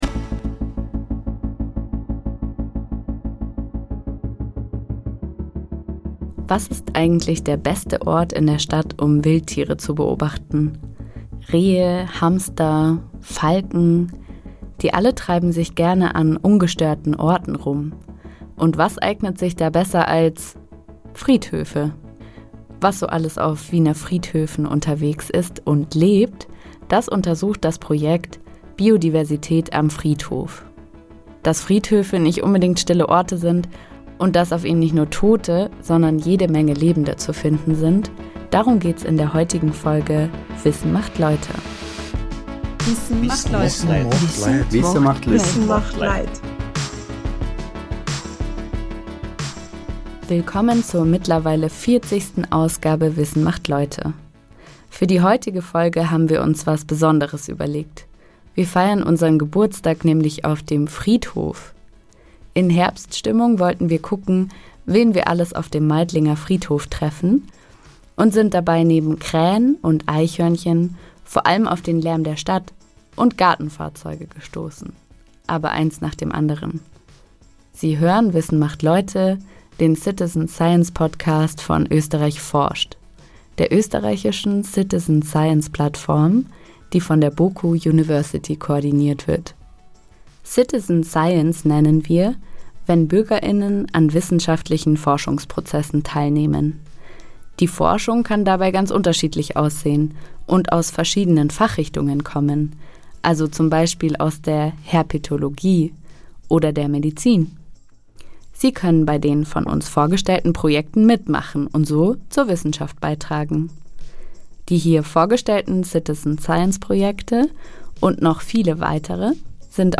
Für unsere 40. Ausgabe haben wir das Studio hinter uns gelassen und einen ganz besonderen Ort aufgesucht. Friedhöfe kennen viele vor allem als Ort der Trauer – er eignet sich jedoch bestens als Forschungsareal!